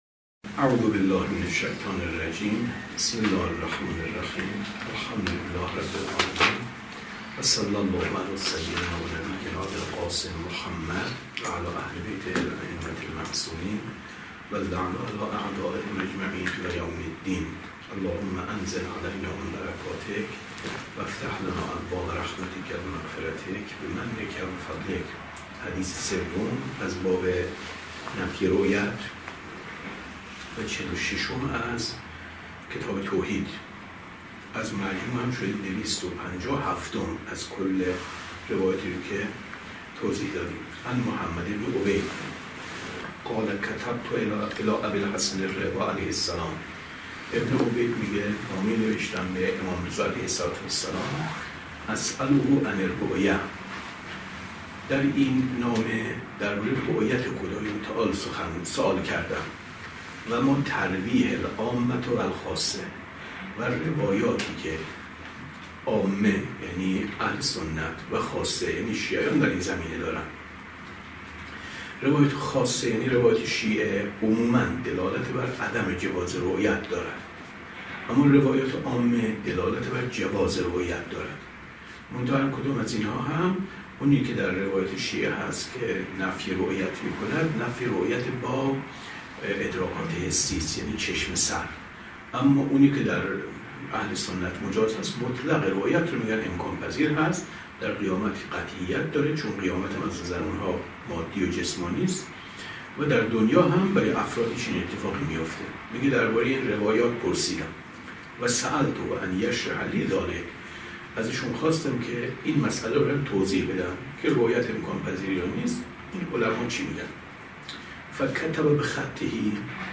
کتاب توحید ـ درس 49 ـ 24/ 10/ 95